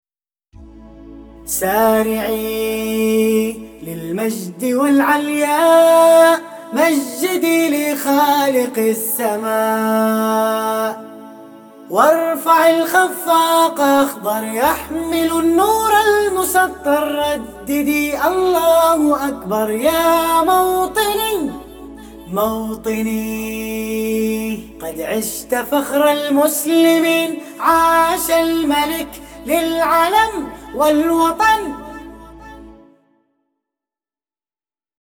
سيكا